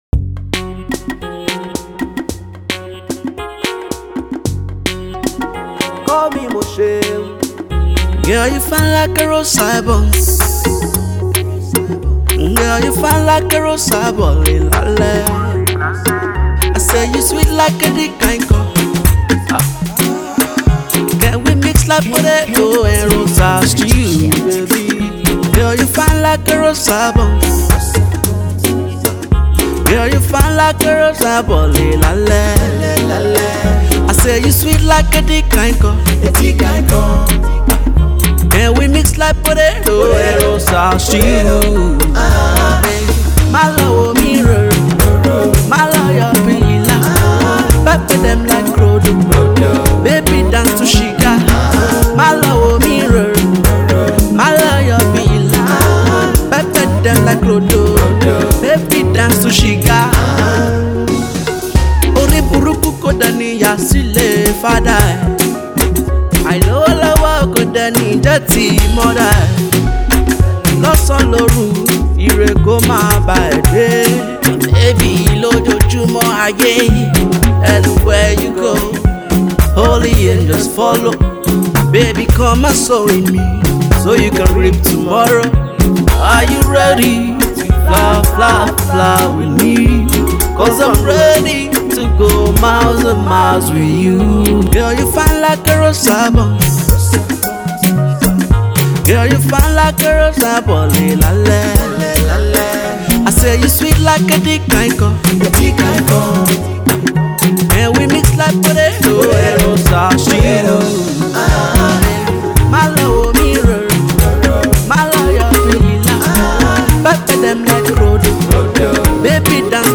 a love song for all ages